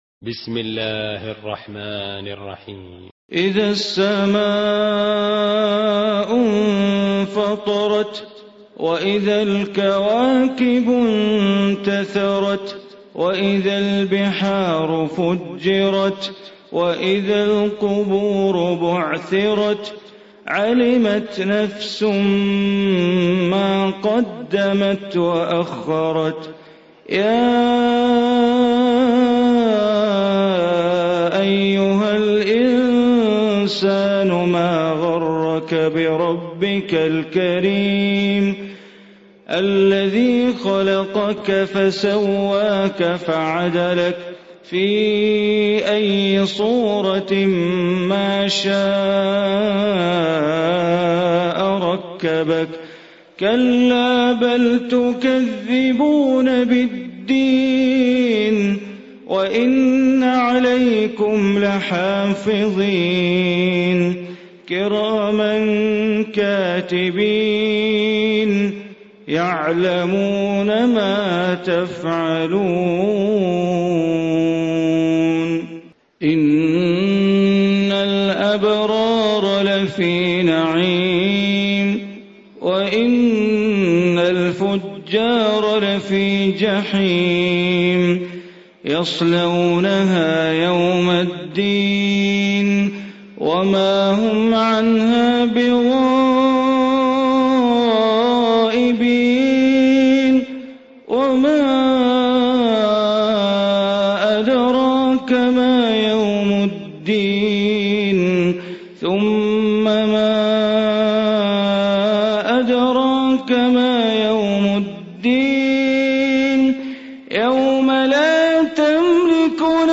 Surah Infitar Recitation by Sheikh Bandar Baleela
Surah Infitar, listen online mp3 tilawat / recitation in Arabic recited by Imam e Kaaba Sheikh Bandar Baleela.